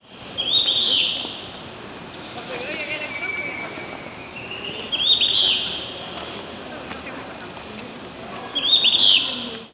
Giant Kingbird: 3 calls
This splendid endemic bird was calling before sunrise. I got the recording with my Canon R5 making a very bad video because of the darkness.
Recorded on Feb. 24, 2025 Recorded in Cuba This splendid endemic bird was calling before sunrise.
Giant_Kingbird--three_calls.mp3